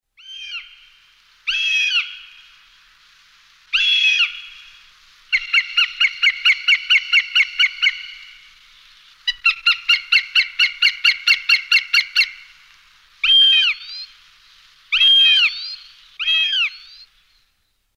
Голос ястреба